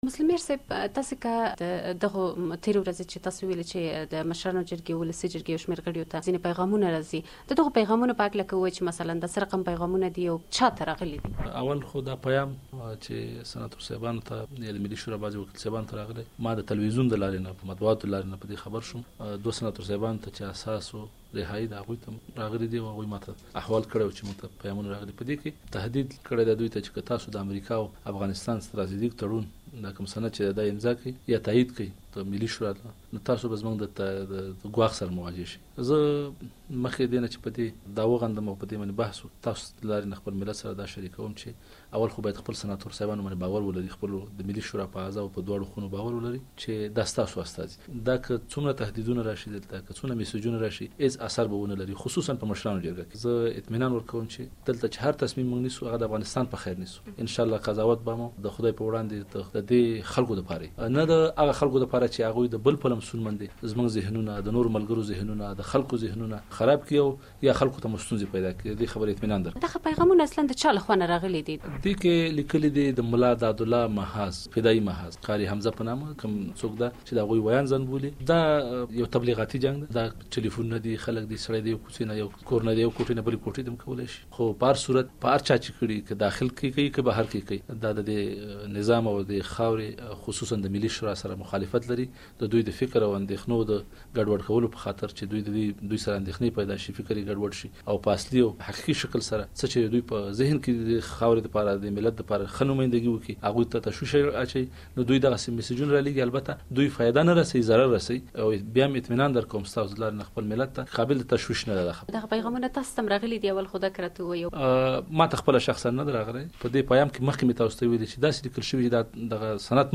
د مشرانو جرګې له مشر سره د پیامونو په اړه مرکه